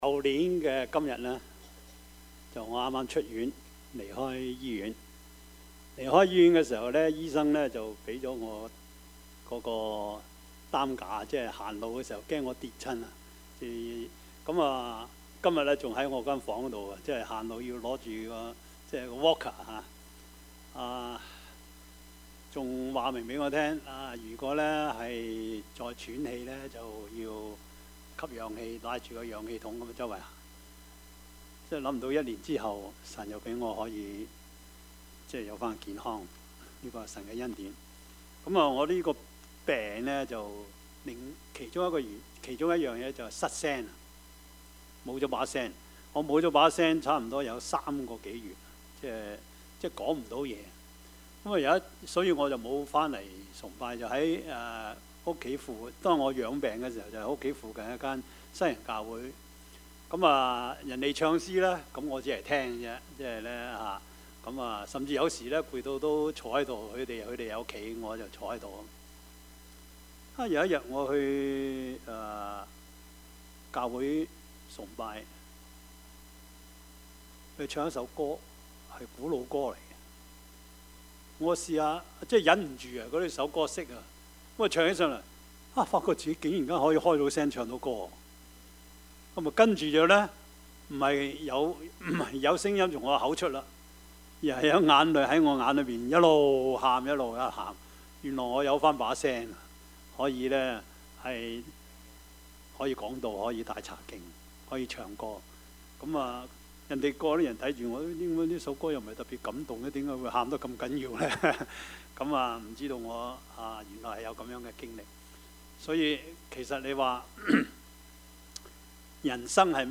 Service Type: 主日崇拜
Topics: 主日證道 « 聖殿風雲 跨越籬笆 »